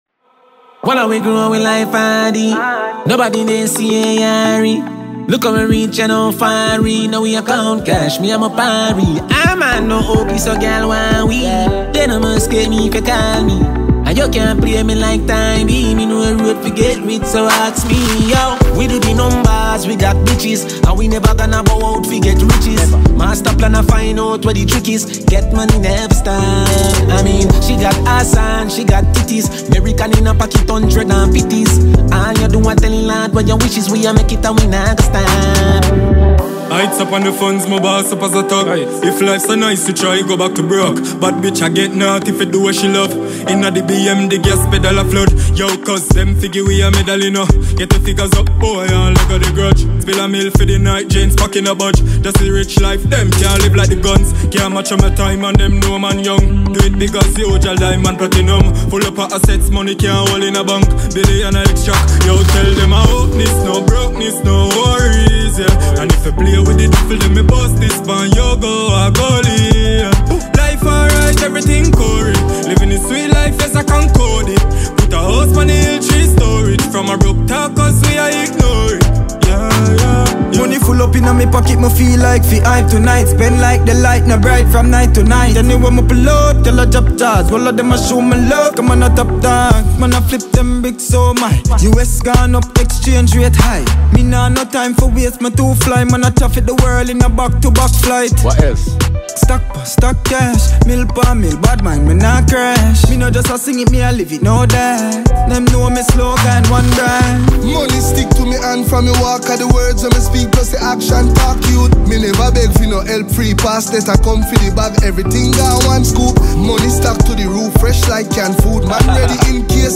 Dancehall/HiphopMusic
melodious